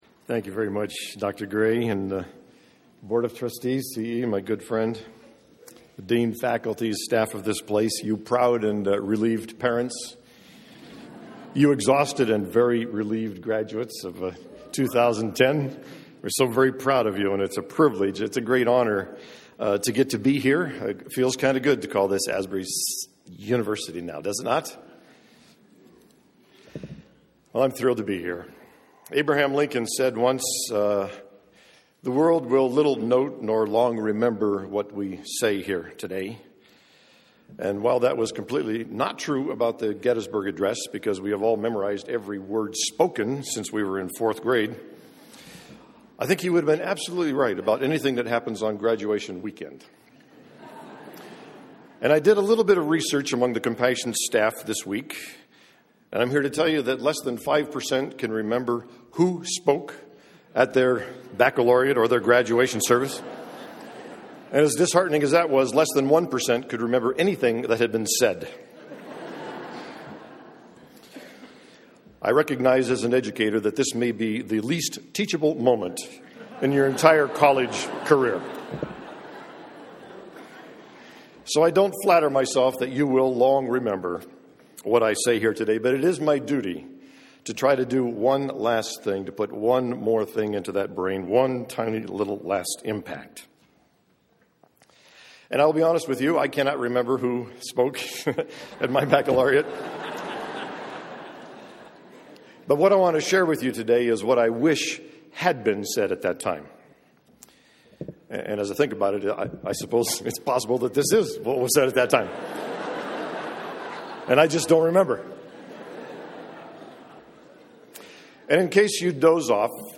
2010 Baccalaureate
Baccalaureate_2010.mp3